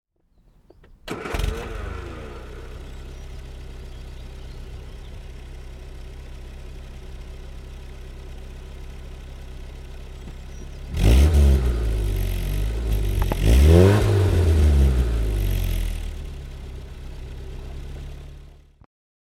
Mini 1000 HL (1982) - Startvorgang